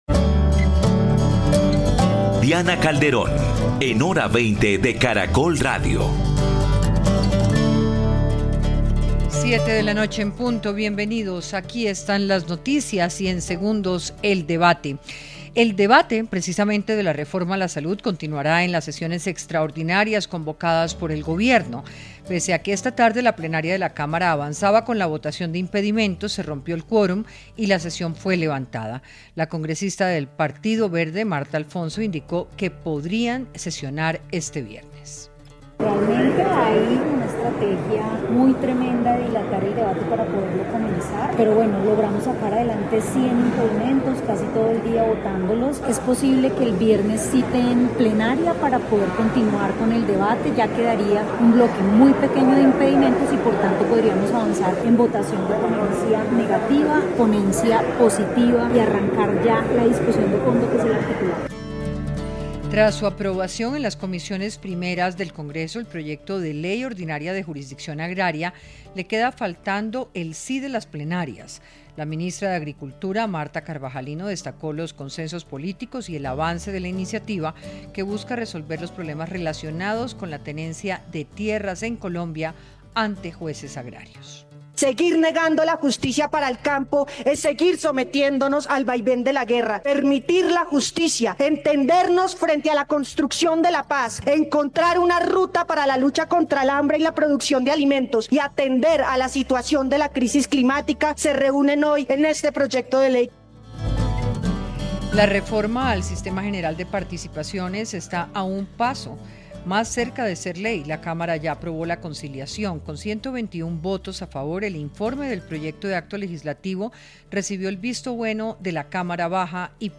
Panelistas creen que la situación de violencia es crítica ante las decisiones tomadas en medio de la política de ‘Paz Tota’l. Otros consideran que la causa está relacionada con la no implementación del Acuerdo de paz en el gobierno anterior.